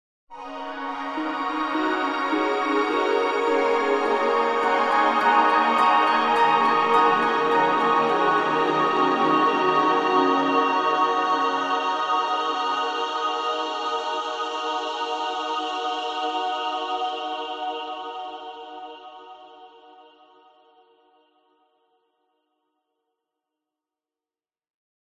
Мечтательный звук эйфории